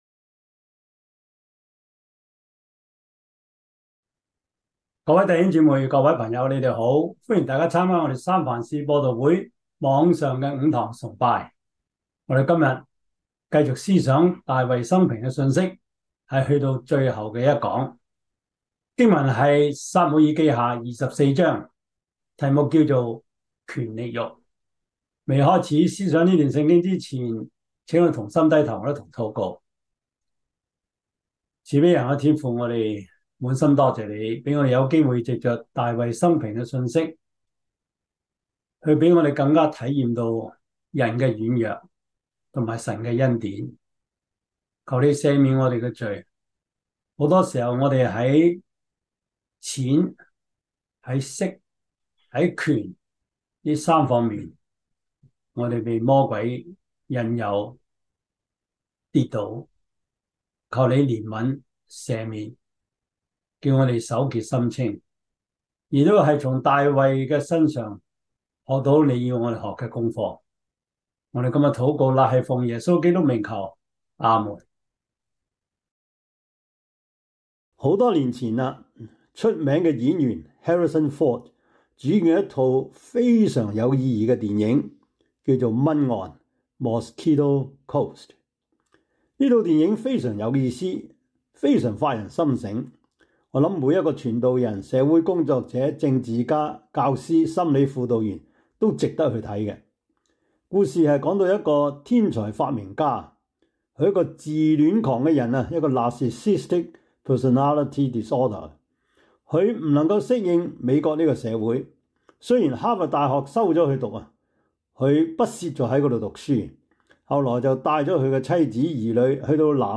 撒母耳記下 24 Service Type: 主日崇拜 撒母耳記下 24 Chinese Union Version